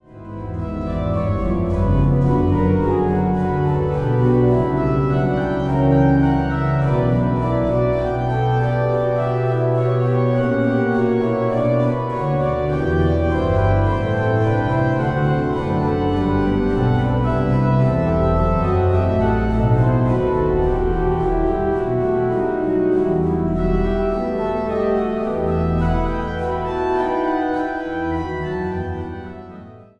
het Walcker orgel van de Riga Dom